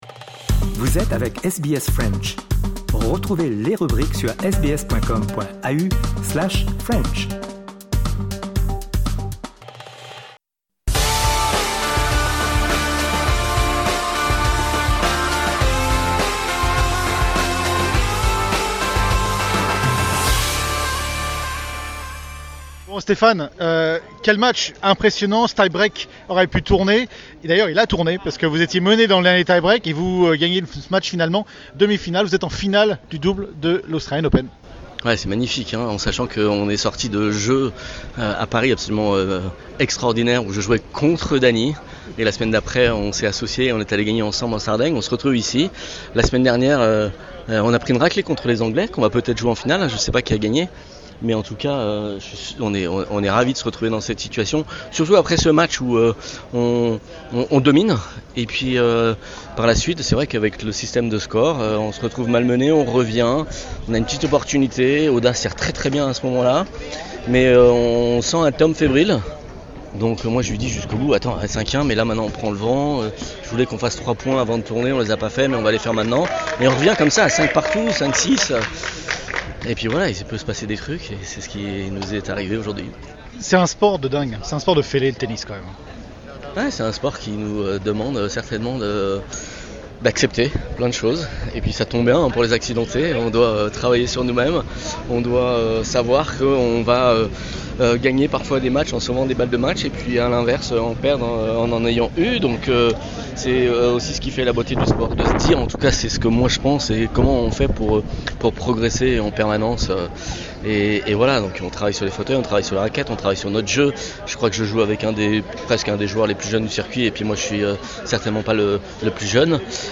Nous l’avons rencontré à l’issue de la demi-finale, pour évoquer cette victoire et revenir aussi sur les Jeux Olympiques de Paris 2024.